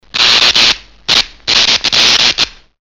zvuk-jelektrichestva_002
zvuk-jelektrichestva_002.mp3